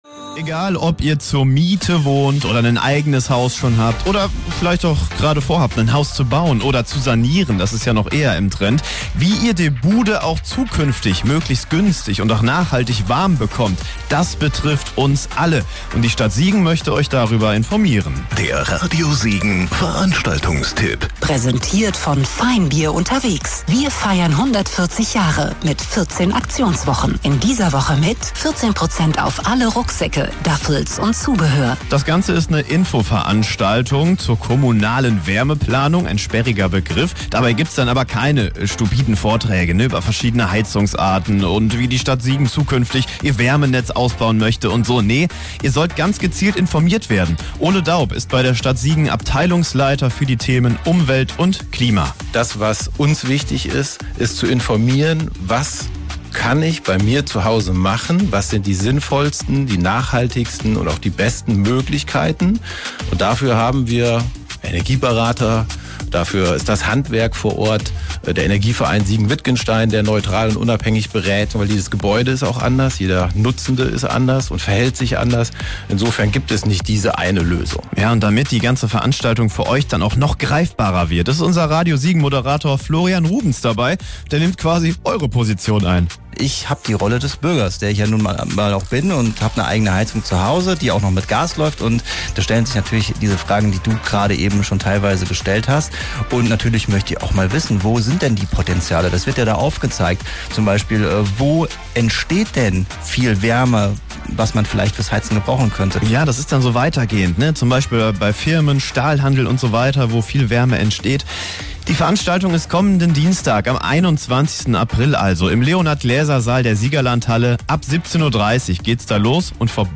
Infoveranstaltung - Kommunale Wärmeplanung in Siegen
mitschnitt-waermeplanung.mp3